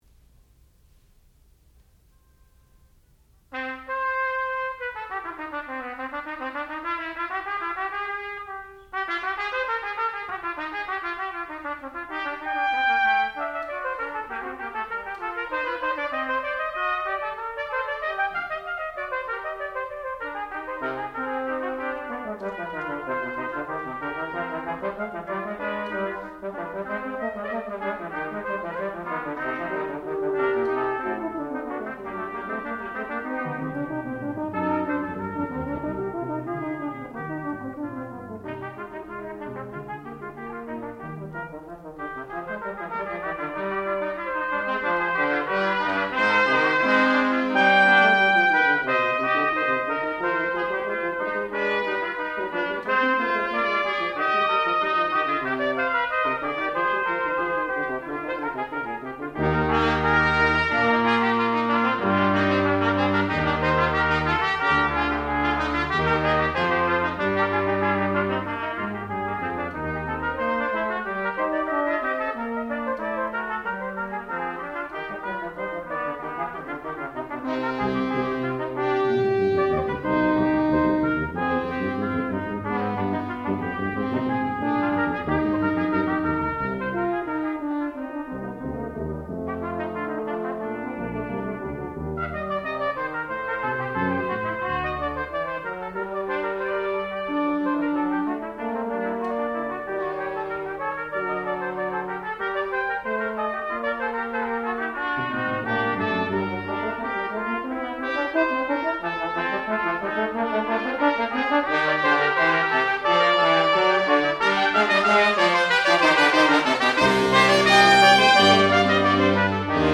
sound recording-musical
classical music
trumpet
trombone